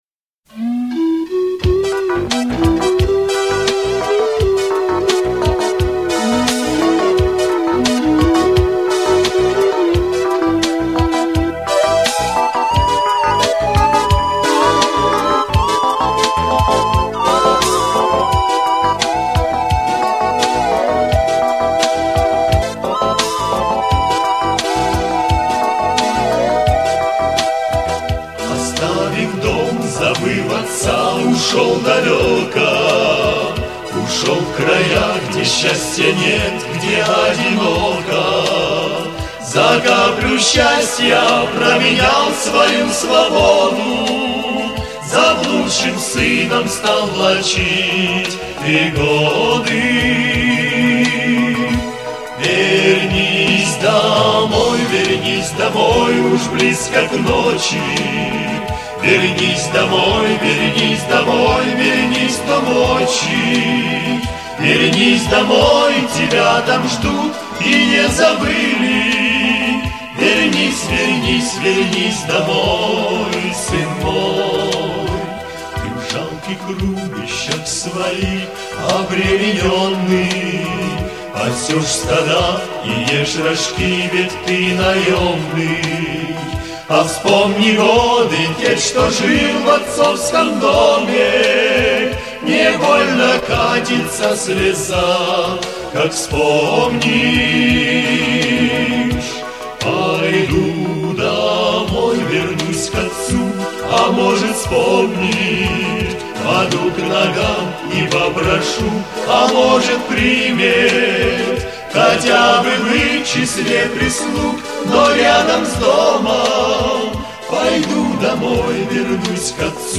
671 просмотр 1111 прослушиваний 147 скачиваний BPM: 108